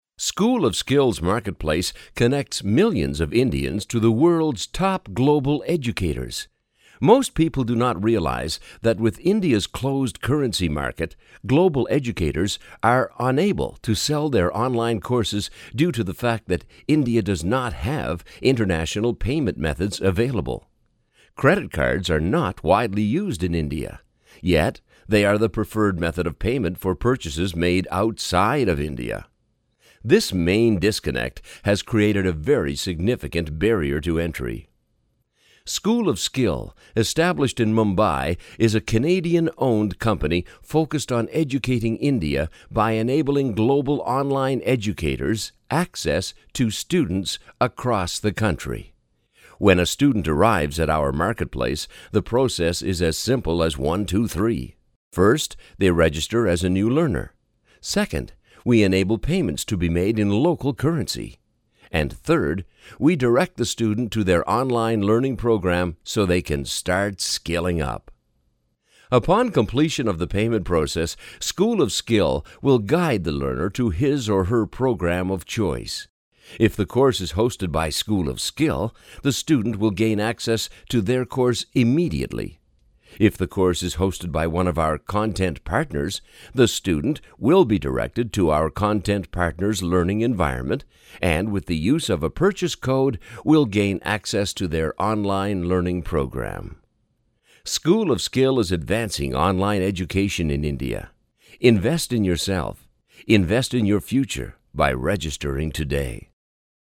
Experienced professional voiceovers, Canadian, American, guaranteed, free auditions
Sprechprobe: Industrie (Muttersprache):